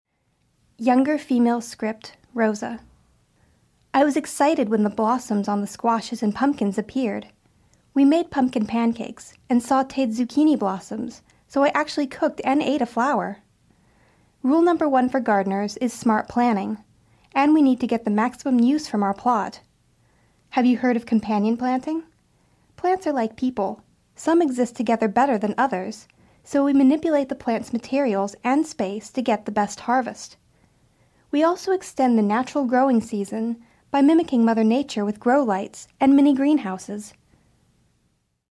young, female voiceover artist, American English, bright, actress, well-trained, intelligent, SAG/AFTRA eligible, NYC actress and voiceover artist, reliable, dependable
middle west
Sprechprobe: eLearning (Muttersprache):